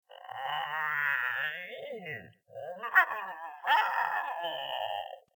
Sind zwei männliche Grauschleicher in der Nähe, kommt es meist zu erbitterten Kämpfen, und ihr tieferes
Kampfgeschrei löst den Lockruf des Weibchens ab.